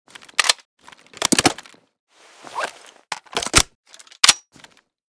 g3_reload_empty.ogg